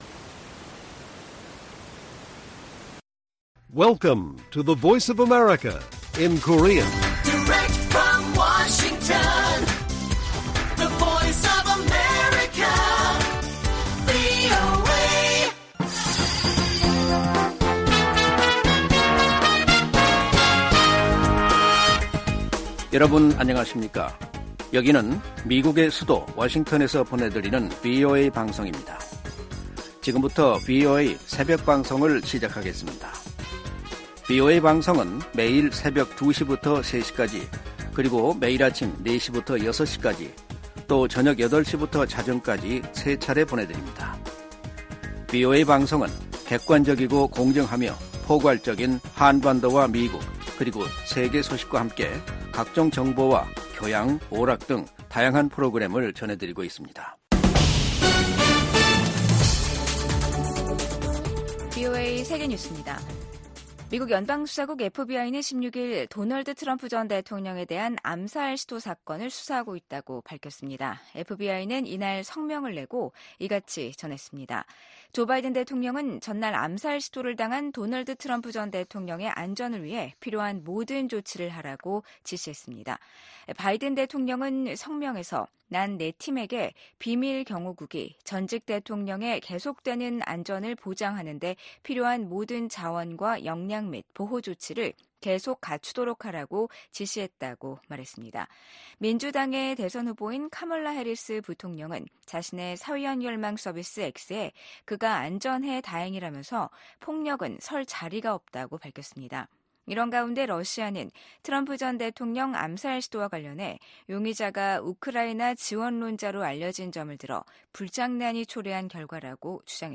VOA 한국어 '출발 뉴스 쇼', 2024년 9월 17일 방송입니다. 미국 백악관은 북한이 우라늄 농축시설을 공개한 것과 관련해 북한의 핵 야망을 계속 감시하고 있다고 밝혔습니다. 북한은 다음달 초 최고인민회의를 열고 헌법 개정을 논의한다고 밝혔습니다.